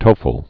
(tōfəl)